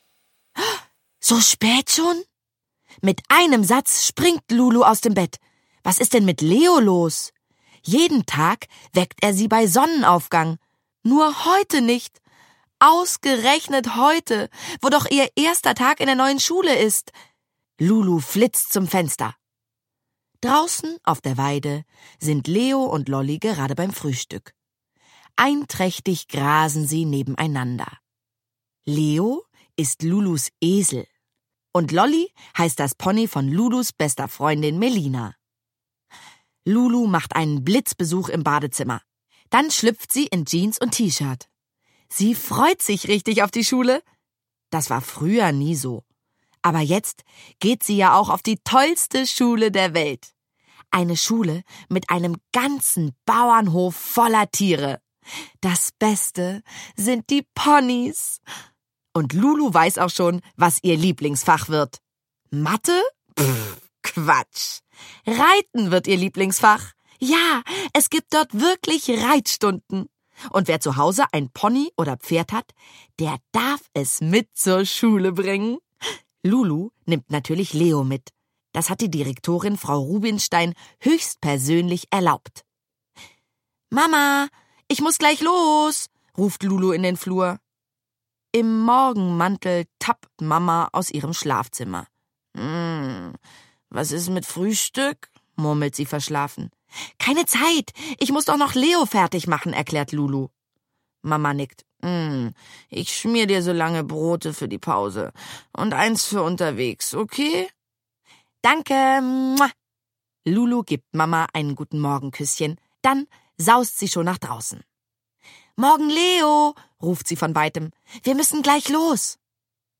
Schlagworte Esel • Hörbuch; Lesung für Kinder/Jugendliche • Pony • Ponyhof • Ponys; Kinder-/Jugendliteratur • Reiten • Tiergeschichten